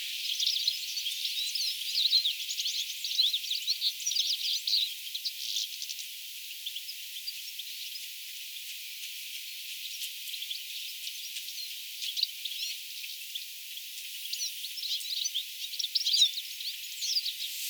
Vuoden alussa siinä lauloi monesti tikli.
vihelteleekö tikli tässä,
vai onko se pikkuvarpusen viheltelyä
tuollaista_viheltelya_tiklilta_vai_voisiko_se_olla_pikkuvarpusen_aanta.mp3